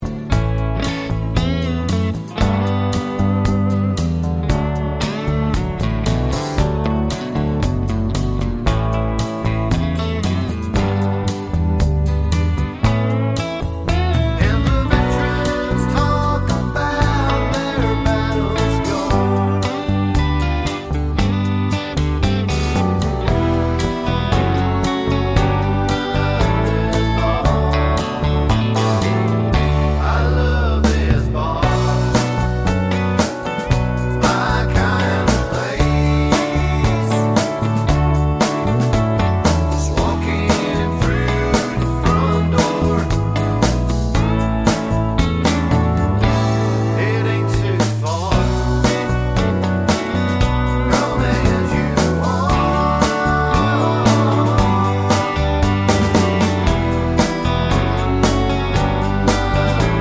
no Backing Vocals Country (Male) 3:51 Buy £1.50